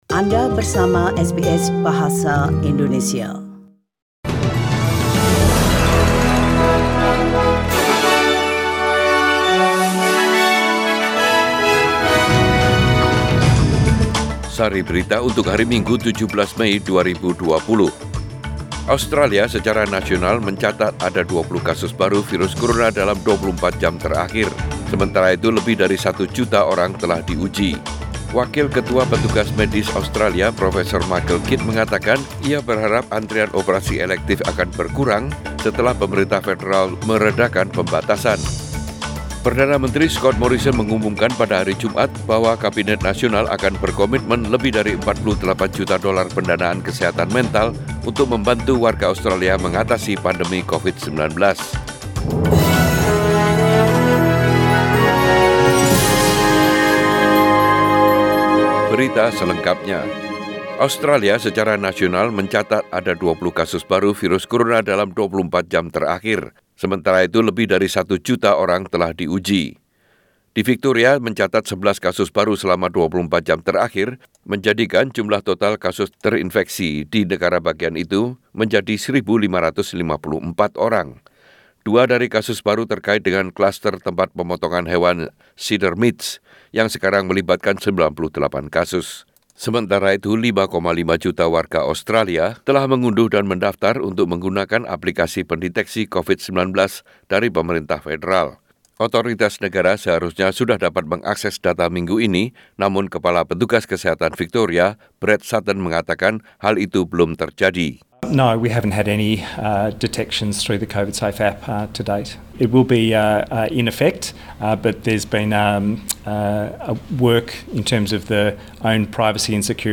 SBS Radio News in Bahasa Indonesia - 17 May 2020